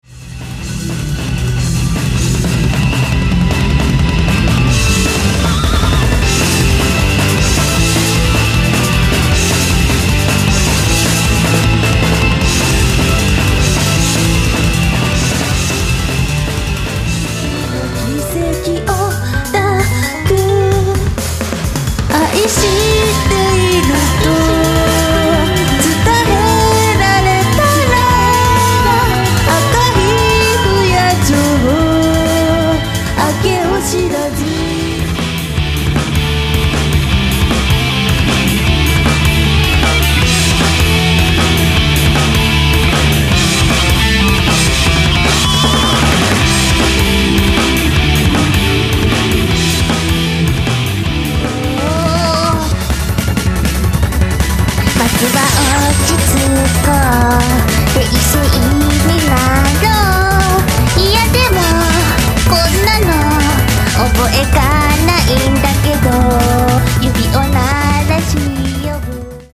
東方ボーカルアレンジCD
ロックからジャズ、ピコピコ電波系までを